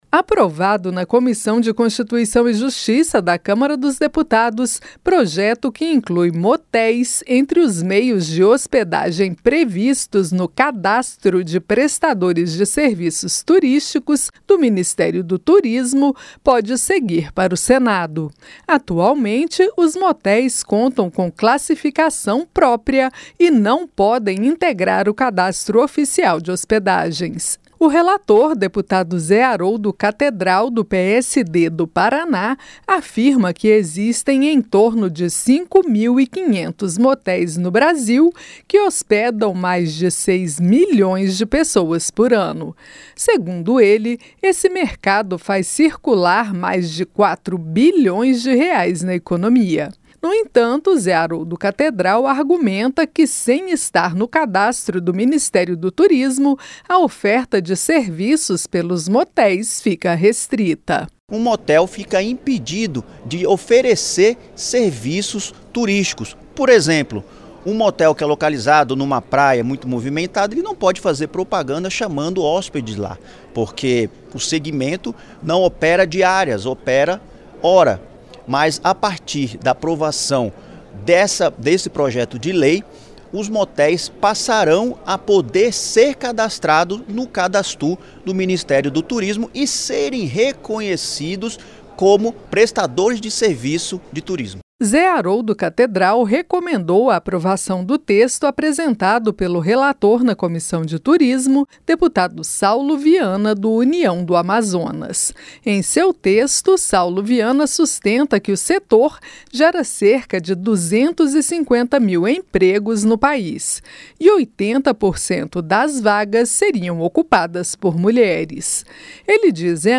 CÂMARA APROVA INCLUSÃO DE MOTÉIS NO CADASTRO DE PRESTADORES DE SERVIÇOS TURÍSTICOS. A REPÓRTER